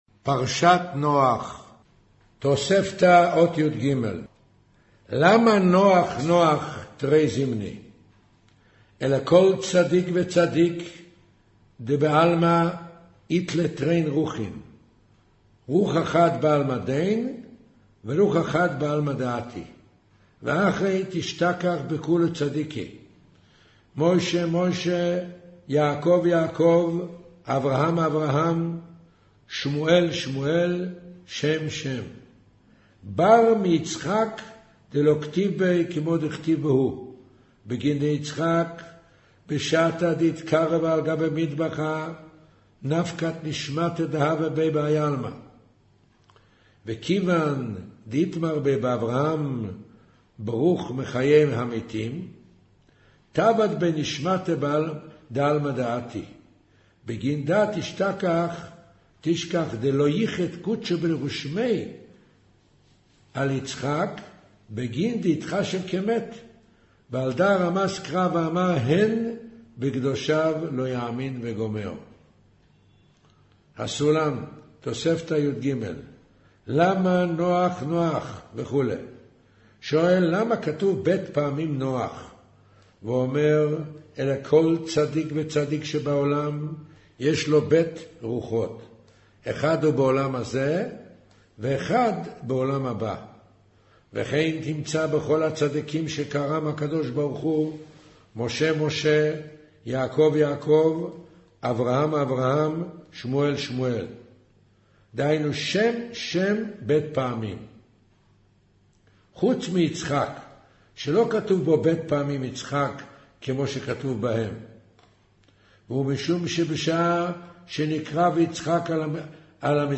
אודיו - קריינות זהר, פרשת נח, מאמר נח ותיבה אות יג'